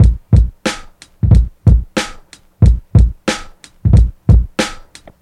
Track 18 (SV Tour) Drums only.wav